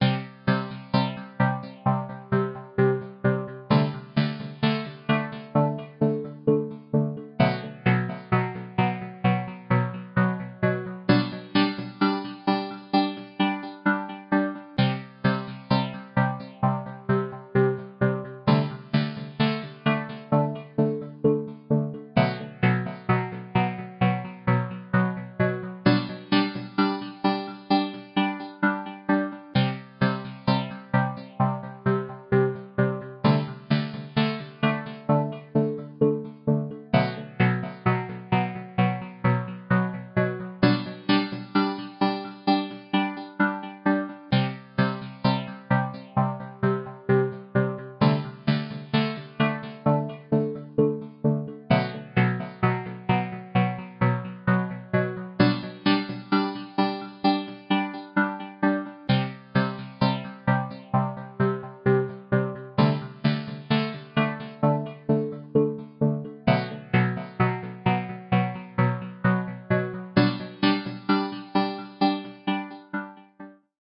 Speed 50%